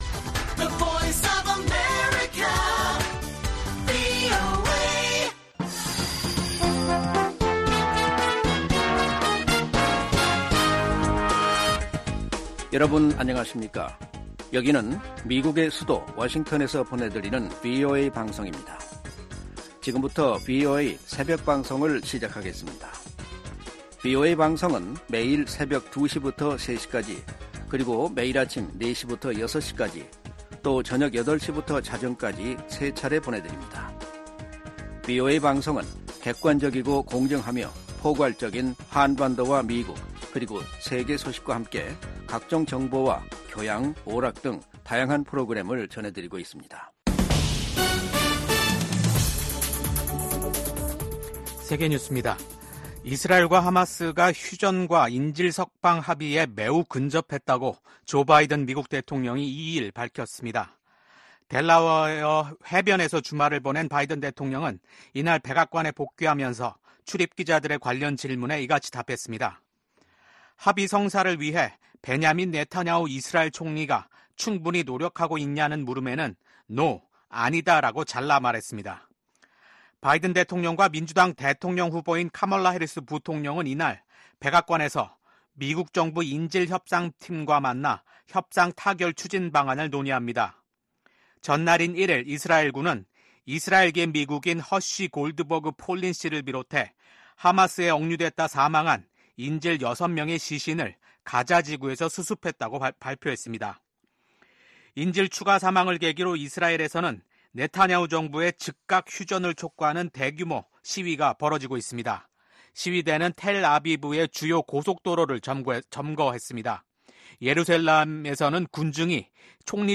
VOA 한국어 '출발 뉴스 쇼', 2024년 9월 3일 방송입니다. 대북 억지력 운용 방안 등을 논의하는 미한 고위급 확장억제전략협의체 회의가 미국 워싱턴에서 열립니다. 미국 정부의 대북정책 목표는 여전히 한반도의 완전한 비핵화라고 국무부가 확인했습니다.